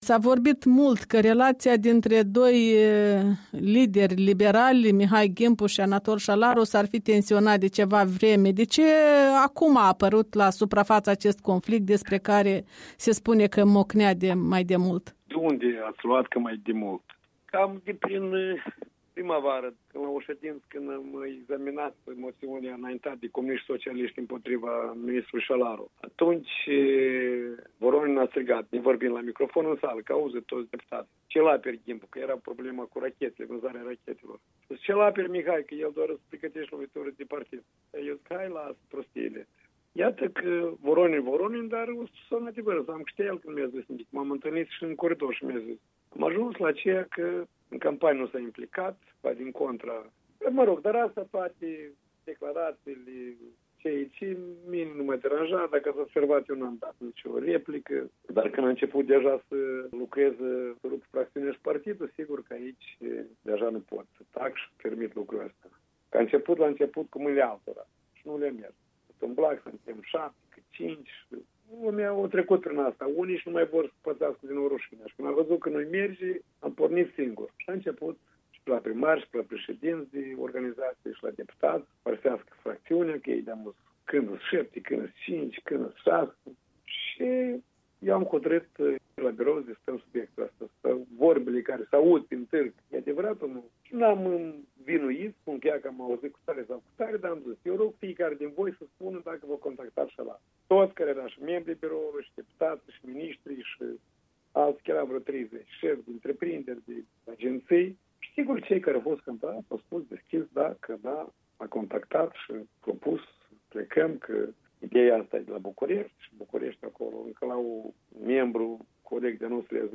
Interviu cu Mihai Ghimpu